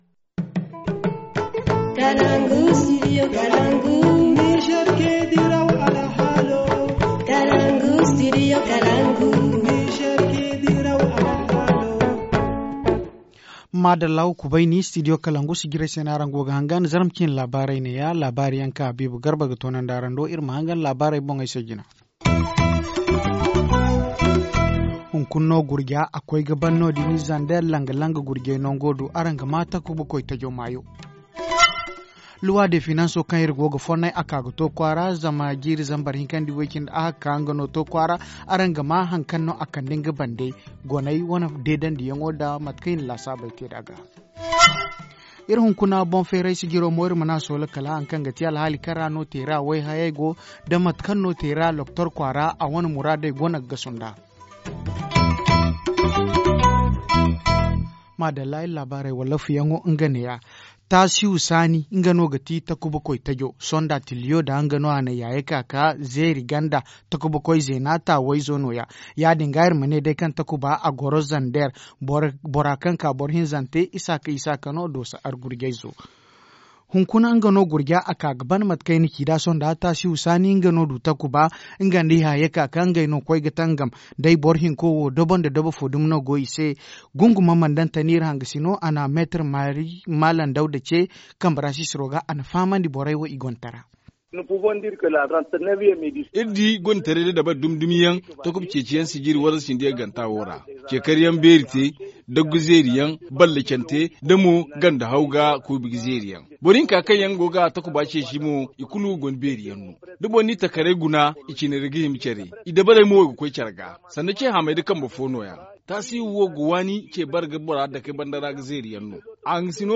2-Application de la loi des finances 2018 au Niger : analyses d’un constitutionaliste et d’un acteur de la société civile.
Journal en français